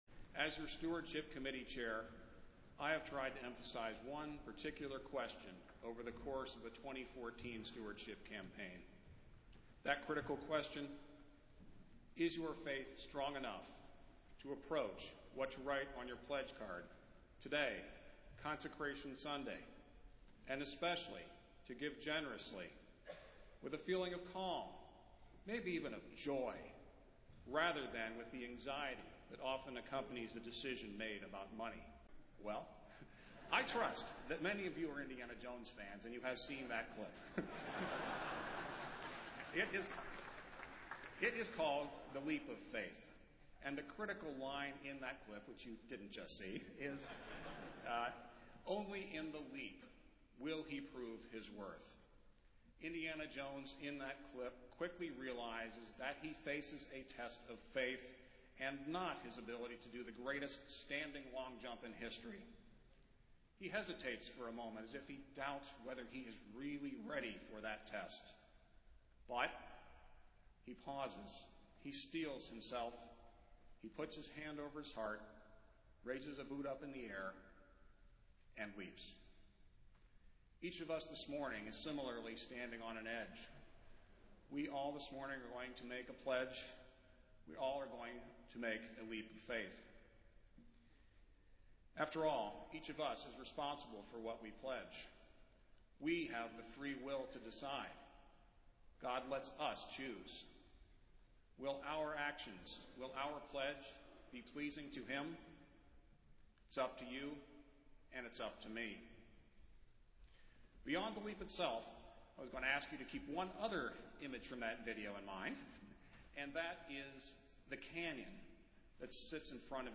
Festival Worship - Consecration Sunday